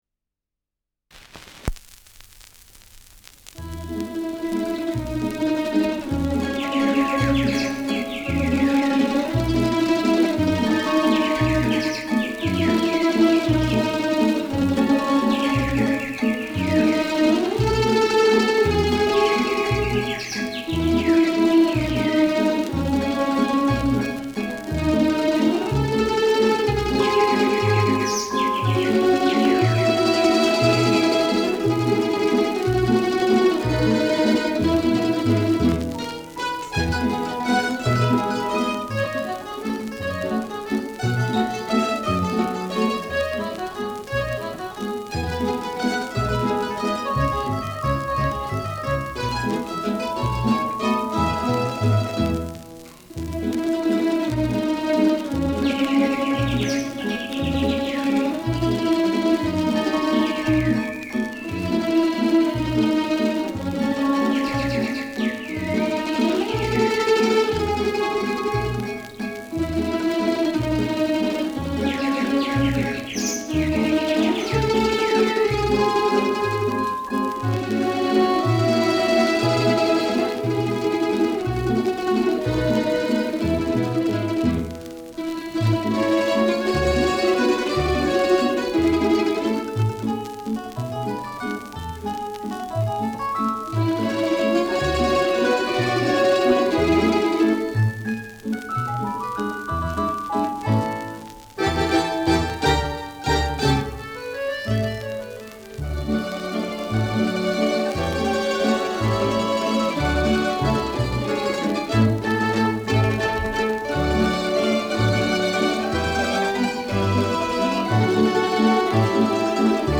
Schellackplatte
Stärkeres Grundknistern